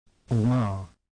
[ ɴ ]
U0274 Uvular nasal.